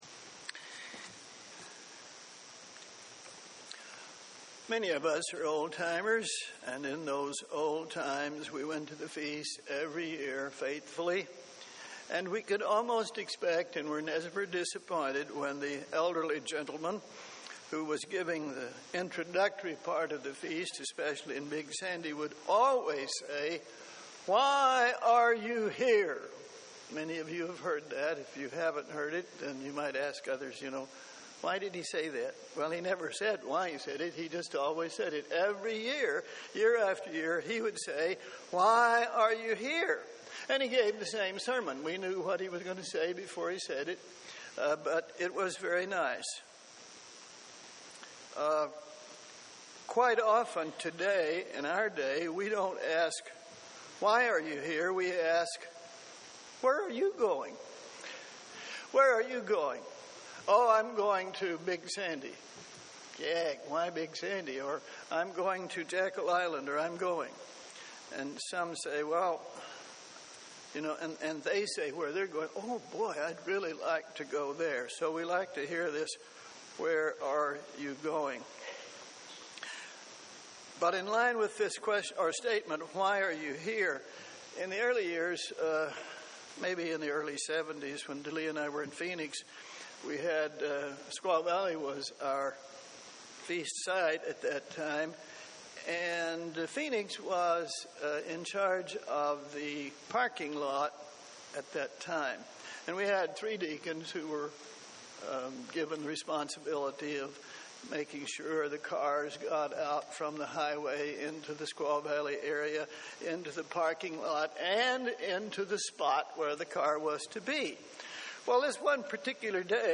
This sermon gives reasons from the Bible for why we must be attending the Feast of Tabernacles.
Given in Columbus, OH